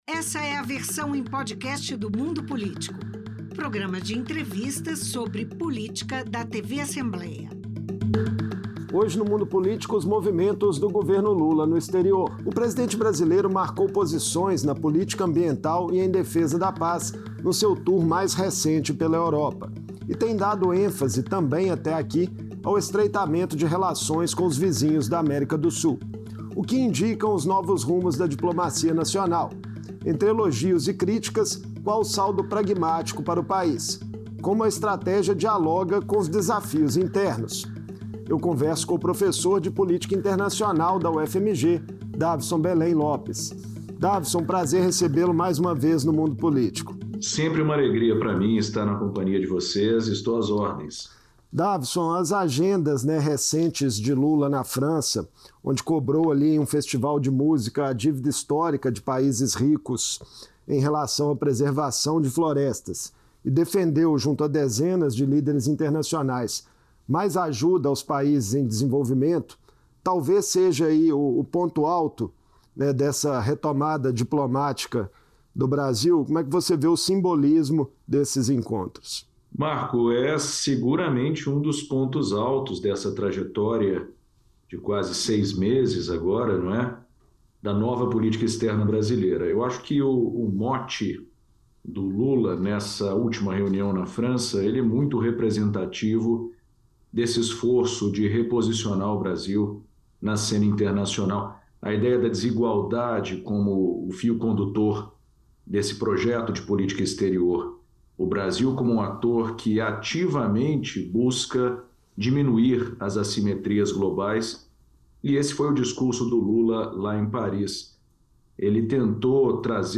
As críticas recentes do presidente Lula à desigualdade entre os países e às nações desenvolvidas que acumulam um grande passivo ambiental resultam de um esforço do Brasil de se reposicionar na cena internacional. Em entrevista